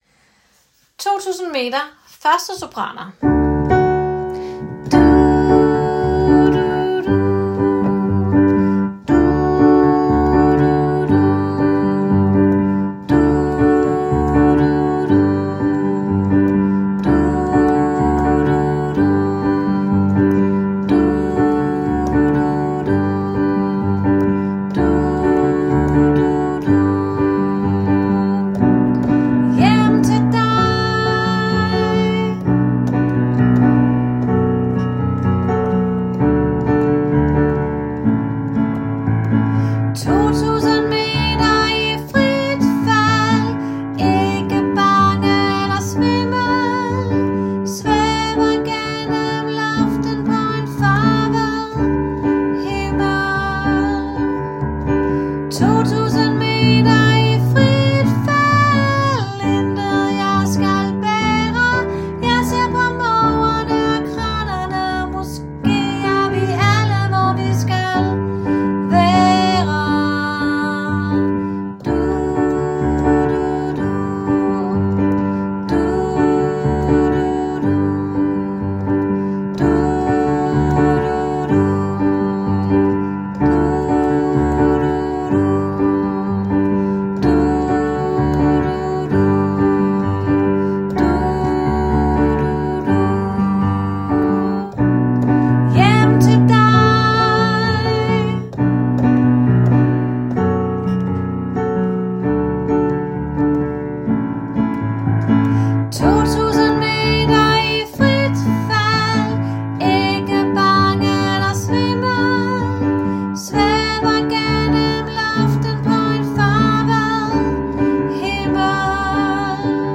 2000 meter – 1. sopran